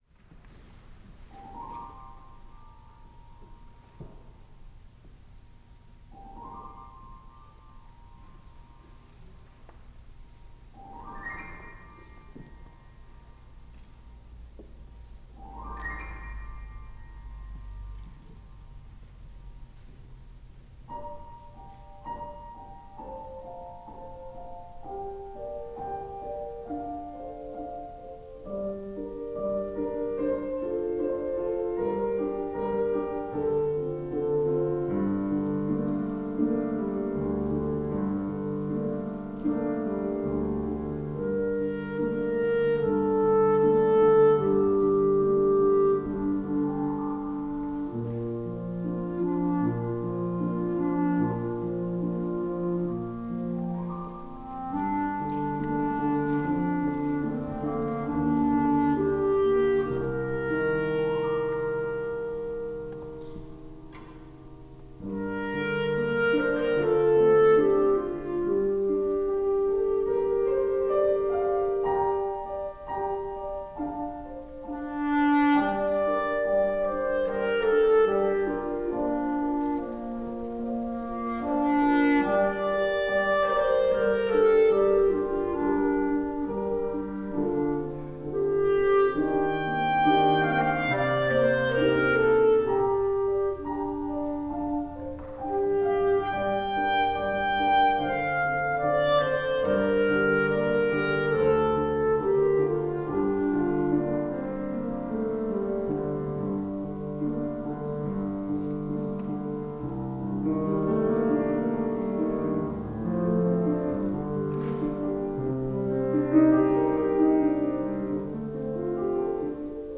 for Clarinet and Piano (2005)